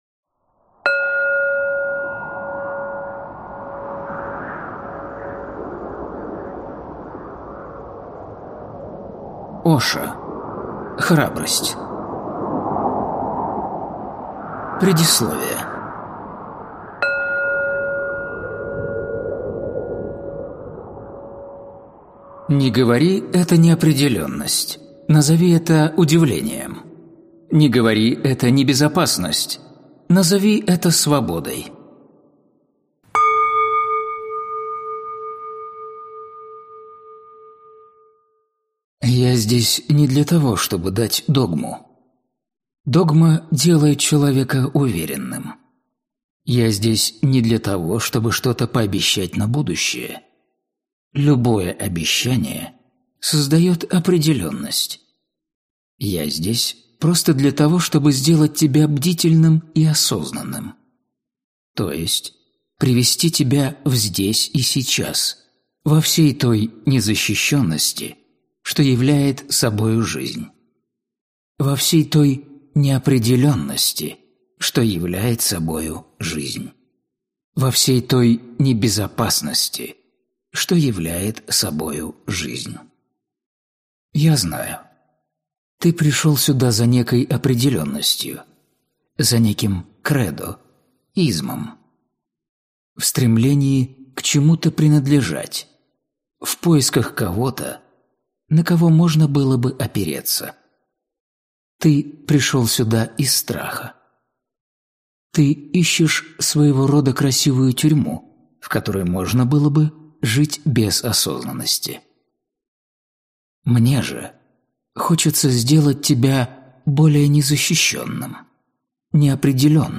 Аудиокнига Храбрость. Радость жить рискуя | Библиотека аудиокниг